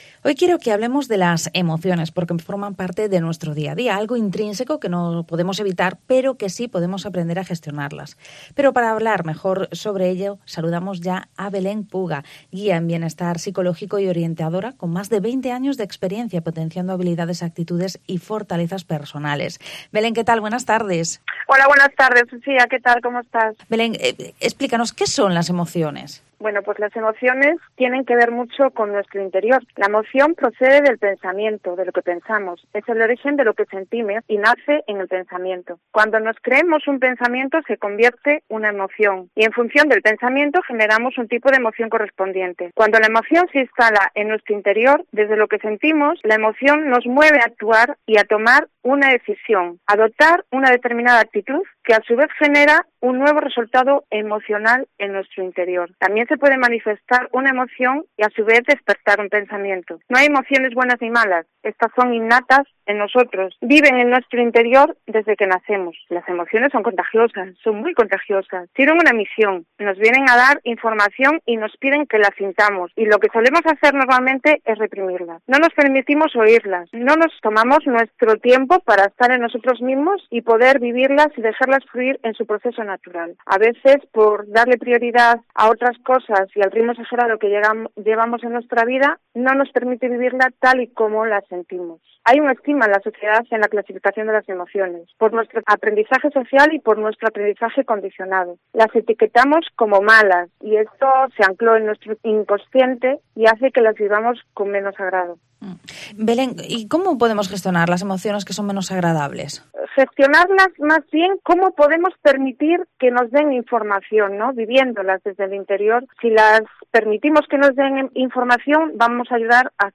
Vigo ENTREVISTA ¿Cómo podemos gestionar nuestras emociones?